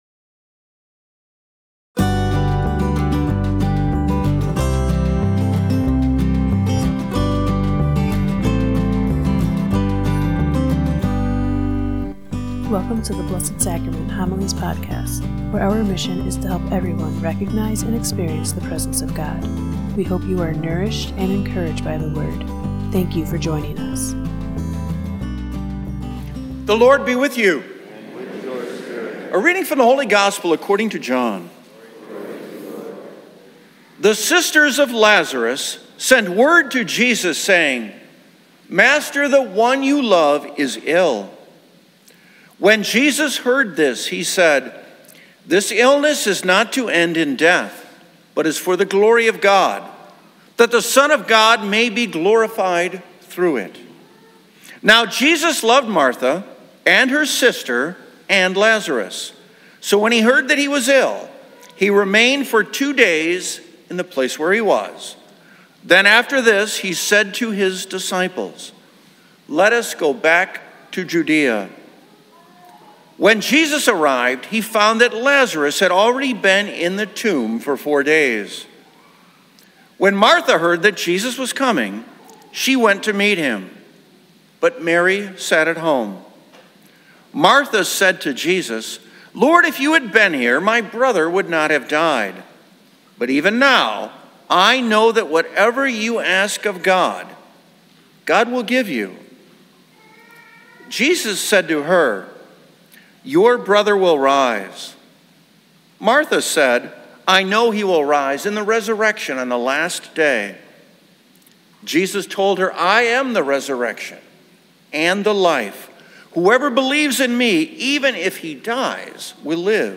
Blessed Sacrament Parish Community Homilies
• (00:00:00) - Gospel
• (00:04:07) - Homily